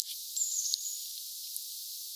tuollainen ehkä sinitiaisen ääntely
tuollainen_ehka_sinitiaisen_aantely.mp3